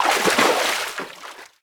TheExperienceLight - A lightened version of the official VIP The Experience soundpack.